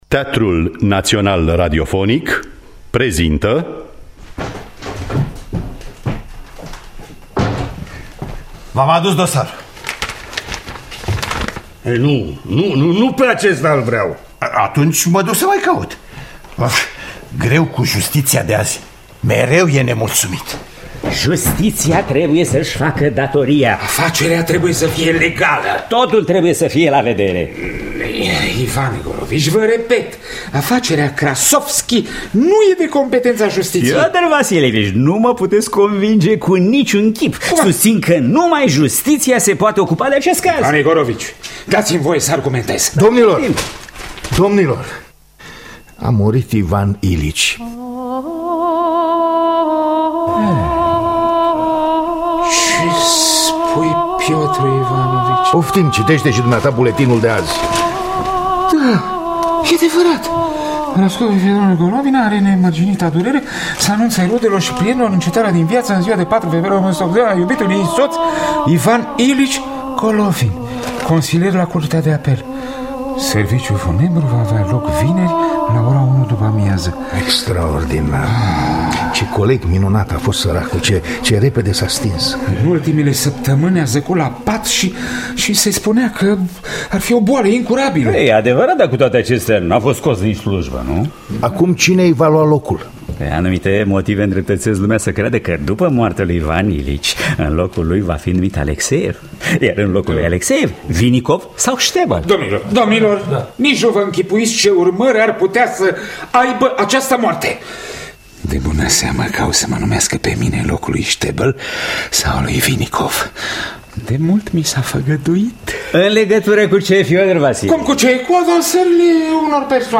Moartea lui Ivan Ilici de Lev Nikolaevici Tolstoi – Teatru Radiofonic Online
Adaptarea radiofonica